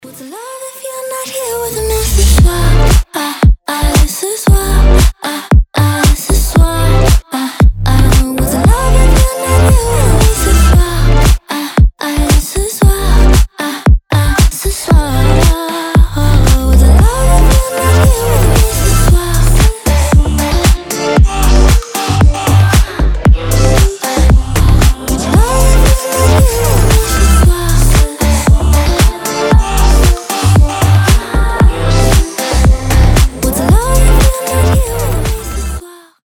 • Качество: 320, Stereo
ритмичные
заводные
женский голос
Dance Pop